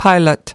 2. pilot /ˈpaɪlət/: phi công